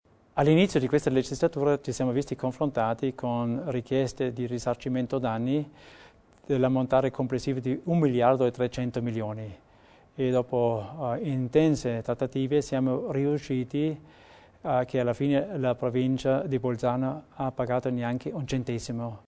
Nell’ambito della serie tematica #5 oggi, 30 luglio, a Palazzo Widmann a Bolzano, l’assessore Theiner ha stilato un breve bilancio dell’attività svolta in questi cinque anni illustrando le sfide affrontate e i traguardi raggiunti nell’arco del suo mandato.